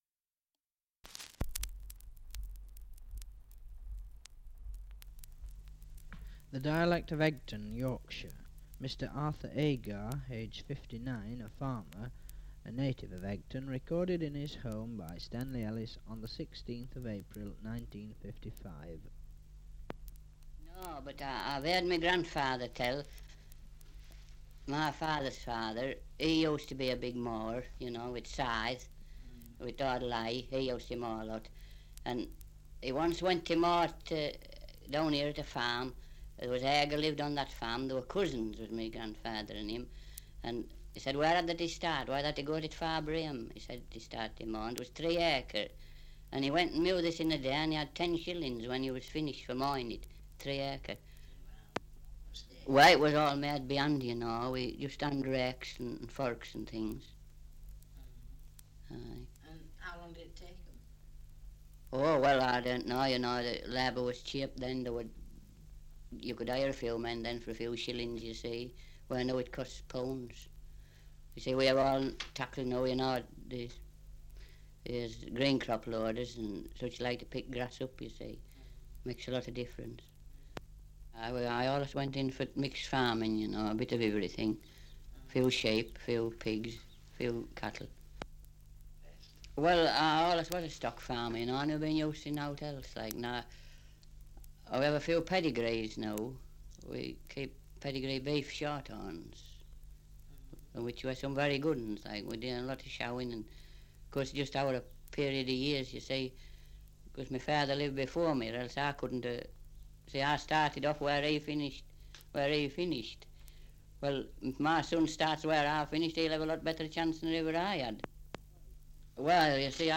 Survey of English Dialects recording in Egton, Yorkshire
78 r.p.m., cellulose nitrate on aluminium